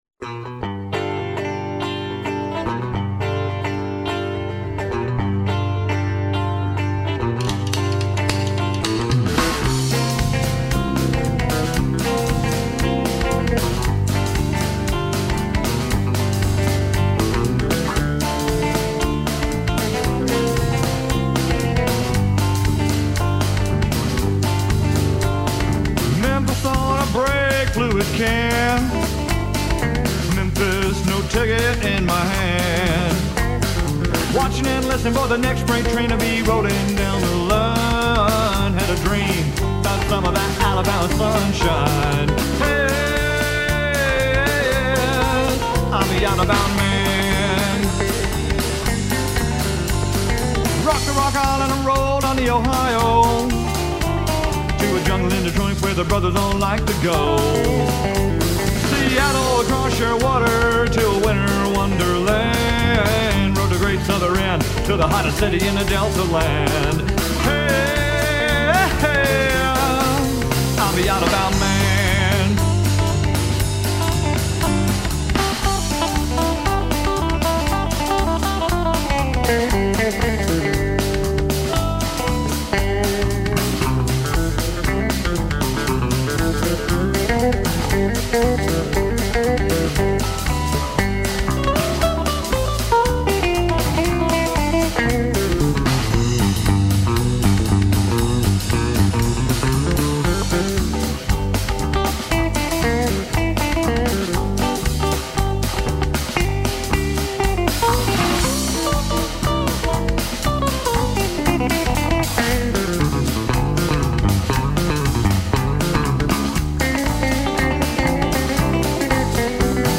open up a four barrel of V-8 ROCK~A~BILLY RHYTHM & BLUES.